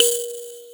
SANZA 4 E3.WAV